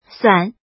怎么读
suan3.mp3